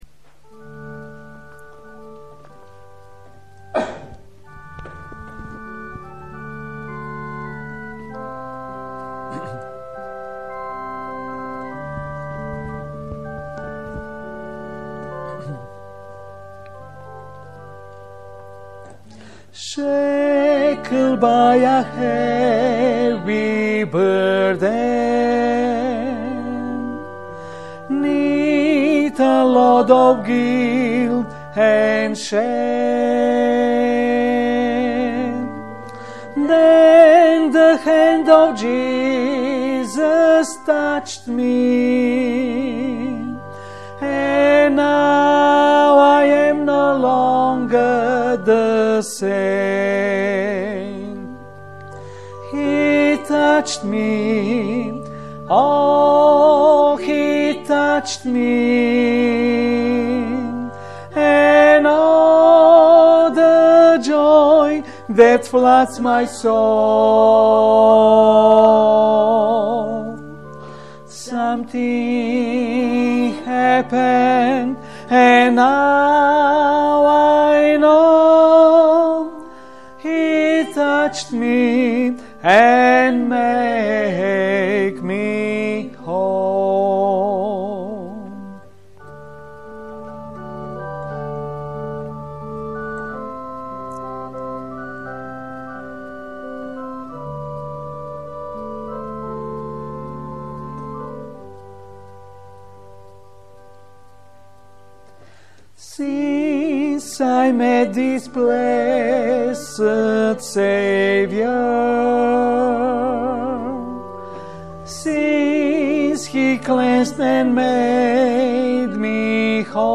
Special performances
Duet